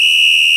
TSW WHISTLE.wav